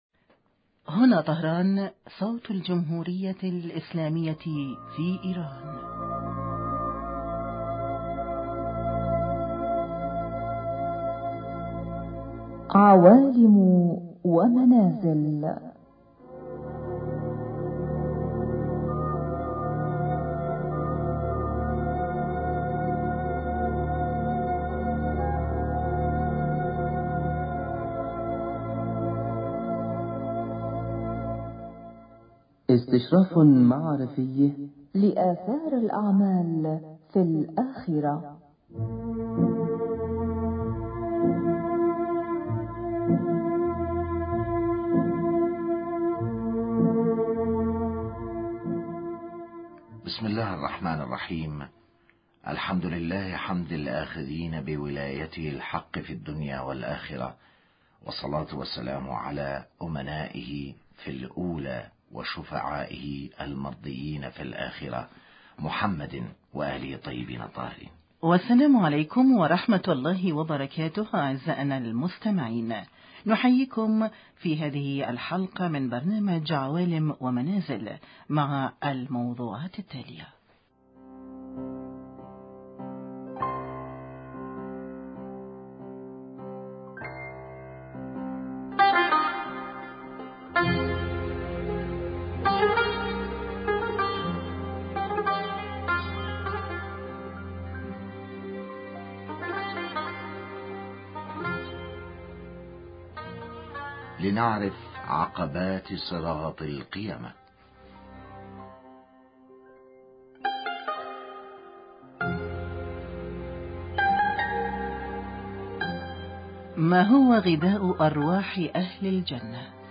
السلام عليكم اعزاءنا مستمعي اذاعة طهران العربية ورحمة الله وبركاته.